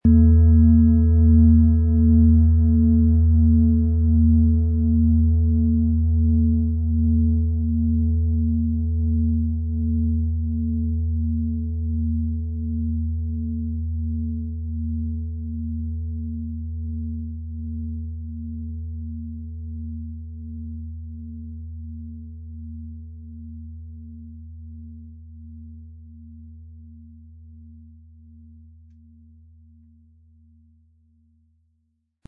• Mittlerer Ton: Mond
Lieferung mit richtigem Schlägel, er lässt die Planetenschale Hopi-Herzton harmonisch und wohltuend schwingen.
PlanetentöneHopi Herzton & Mond
MaterialBronze